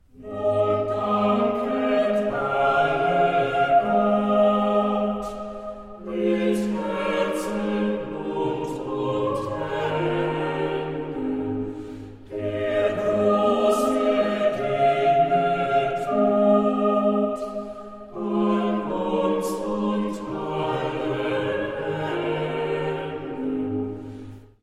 Uitgevoerd door Vocalconsort Berlin.